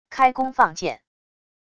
开弓放箭wav音频